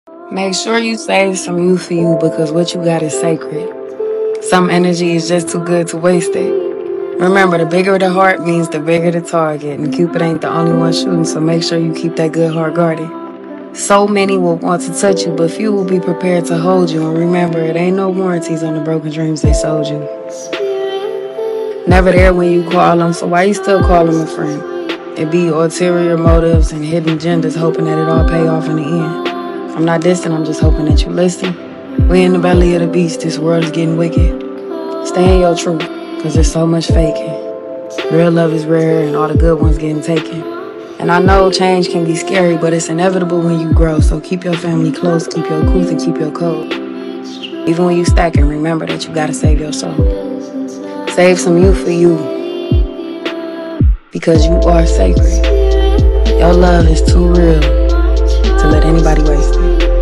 Don’t mind my washer it’s loud for no reason but this ice too soft no crunches